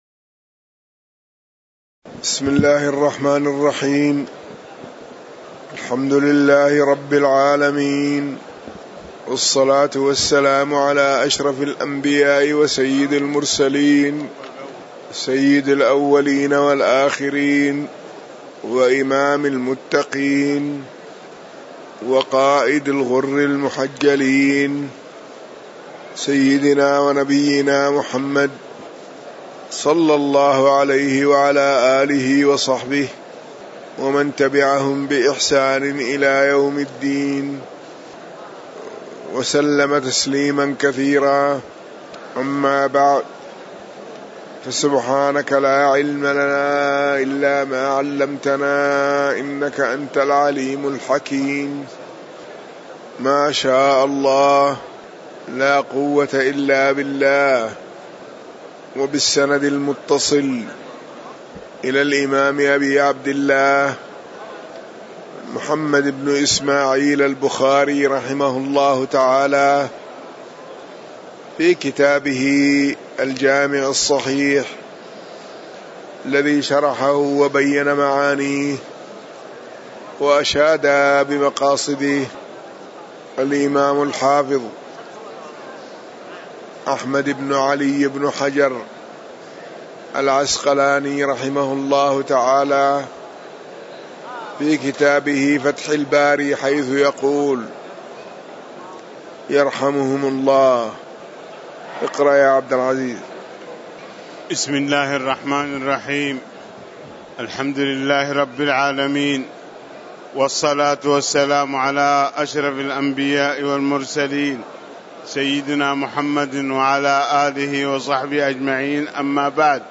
تاريخ النشر ١٤ صفر ١٤٤٠ هـ المكان: المسجد النبوي الشيخ